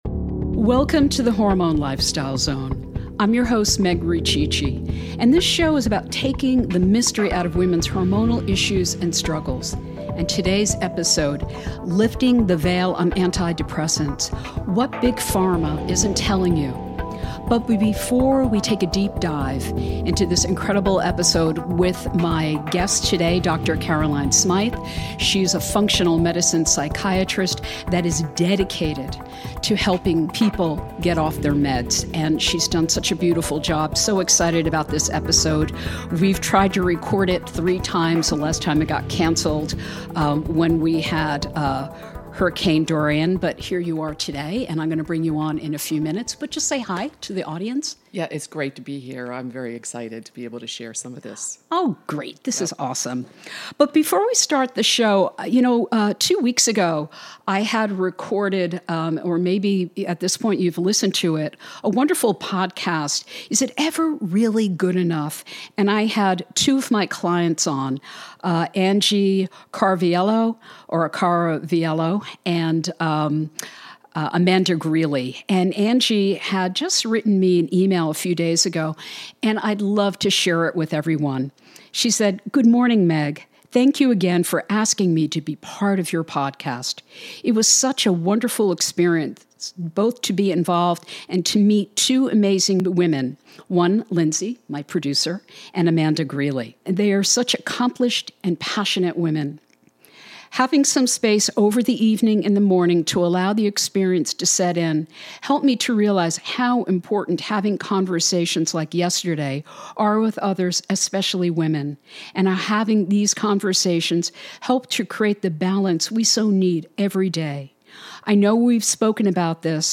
MD- an extraordinary functional medicine psychiatrist.